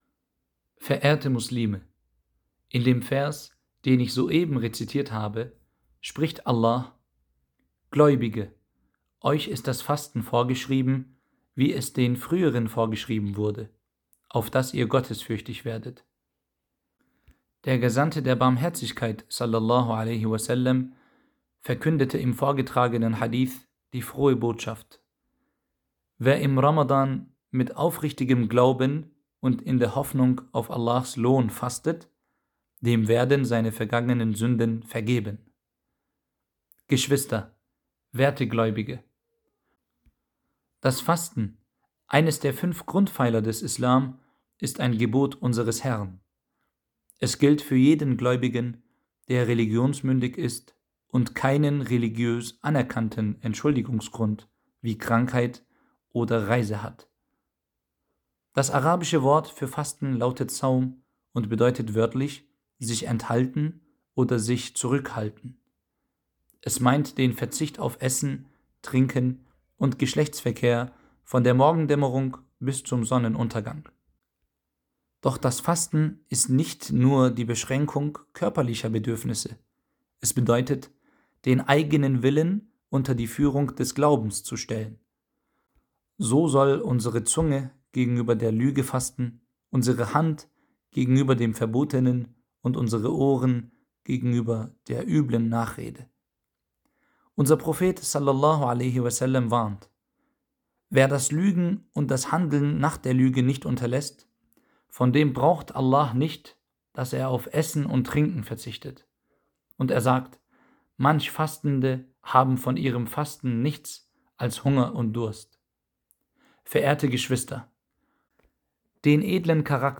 Freitagspredigt - Audio